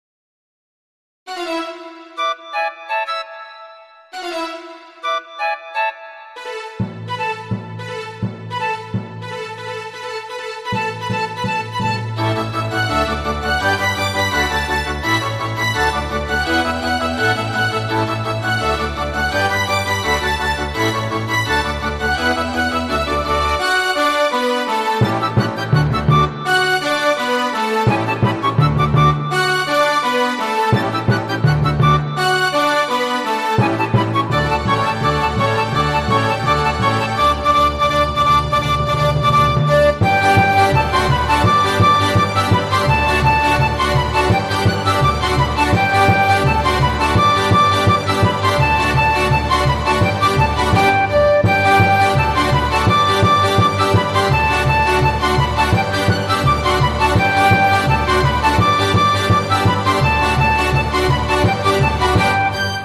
クラシック音楽の曲名